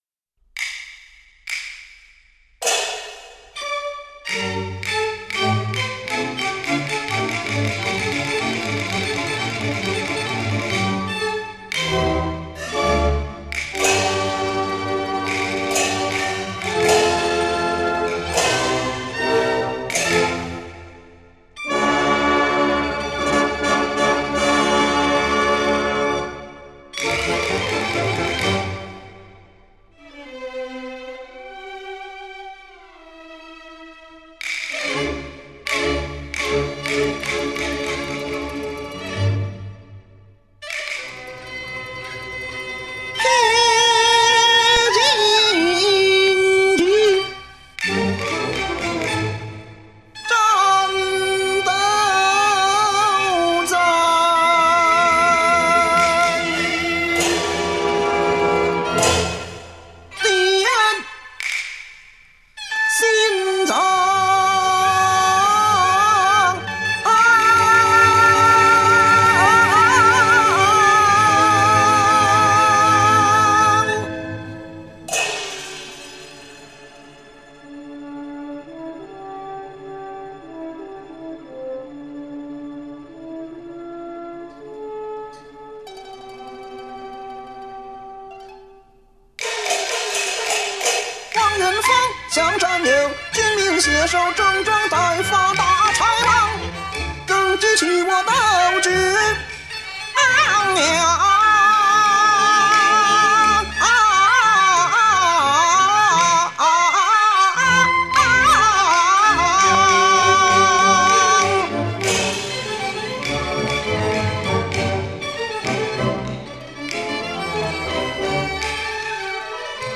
一直在咳嗽，只好把几年前的旧唱翻新，和同志们拜年了！